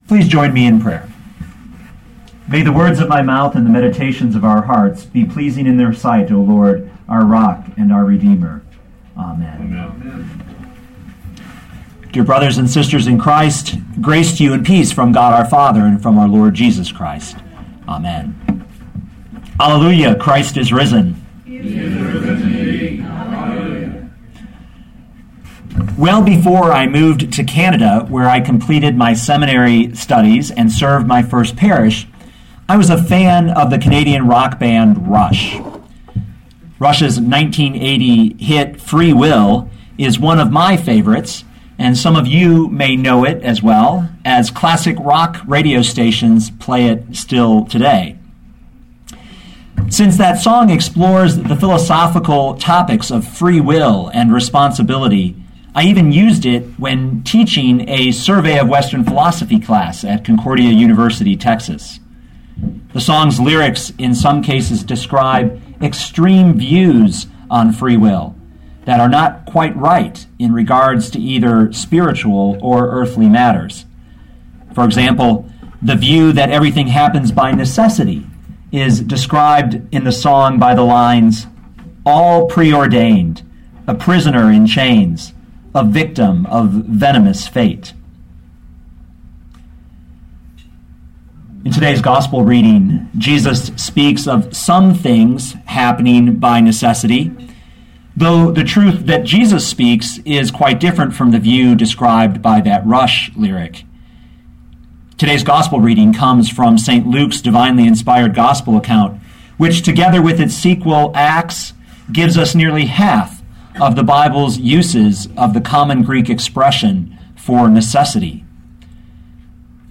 2012 Luke 24:44-47 Listen to the sermon with the player below, or, download the audio.